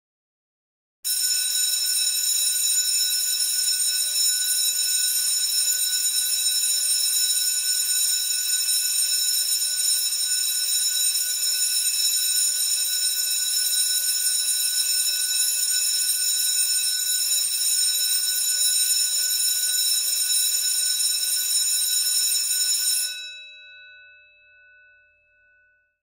جلوه های صوتی
دانلود صدای زنگ مدرسه 3 از ساعد نیوز با لینک مستقیم و کیفیت بالا